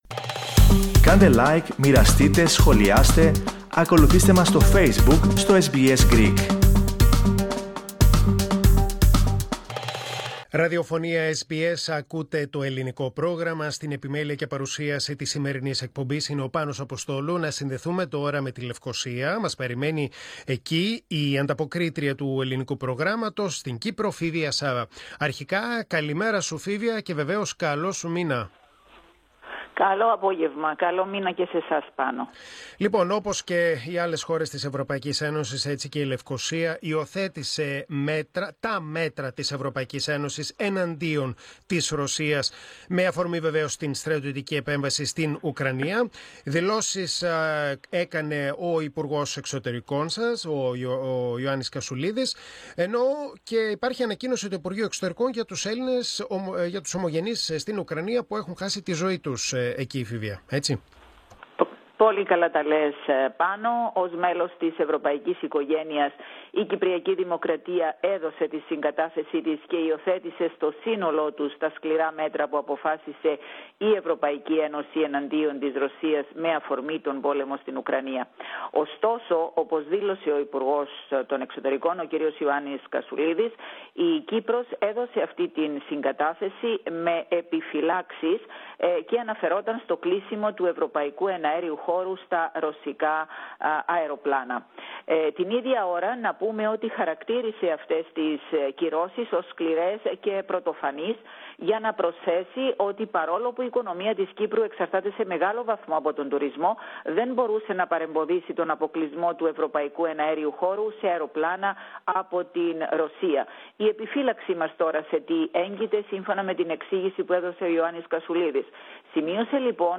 Ακούστε ολόκλη την ανταπόκριση από την Κύπρο, πατώντας το σύμβολο στο μέσο τηςε κεντρικής φωτιογρφίας.